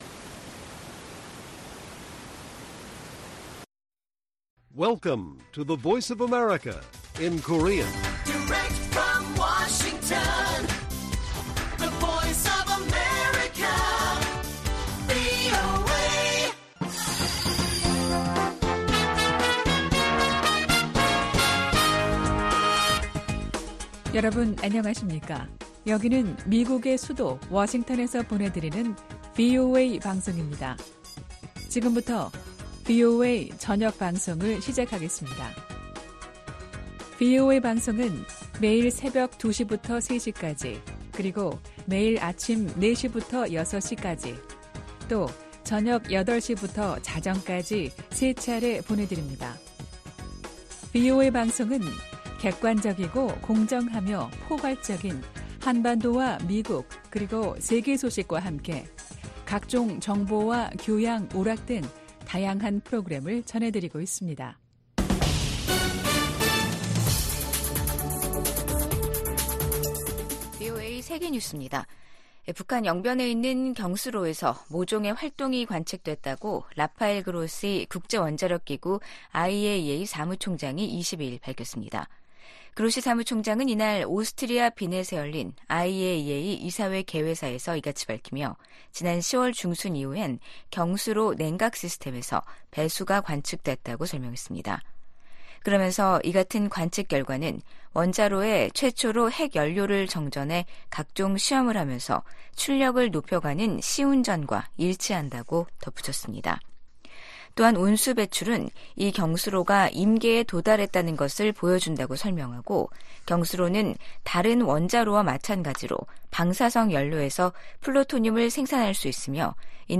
VOA 한국어 간판 뉴스 프로그램 '뉴스 투데이', 2023년 12월 22 일 1부 방송입니다. 미국이 탄도미사일 개발과 발사가 방위권 행사라는 북한의 주장을 '선전이자 핑계일 뿐'이라고 일축했습니다. 미 국방부는 북한 수뇌부를 제거하는 '참수작전'이 거론되자 북한에 대해 적대적 의도가 없다는 기존 입장을 되풀이했습니다. 이스라엘 정부가 북한의 탄도미사일 발사를 '테러 행위'로 규정했습니다.